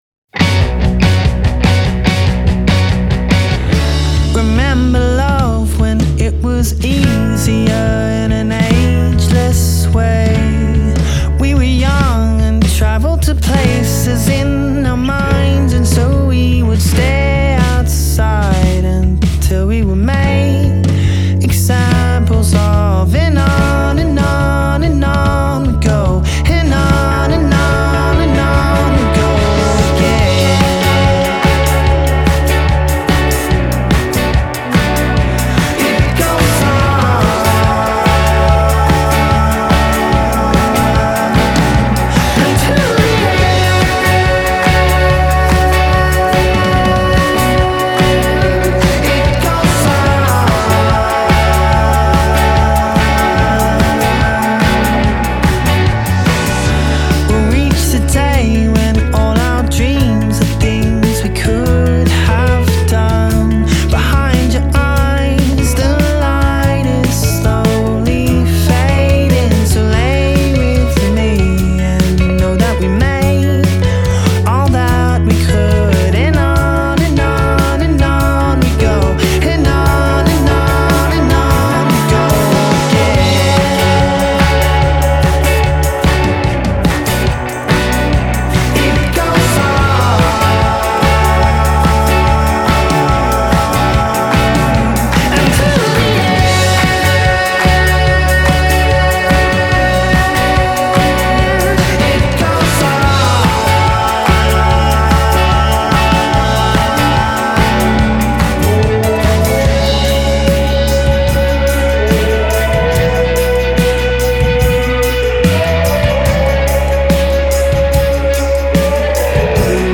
indie pop music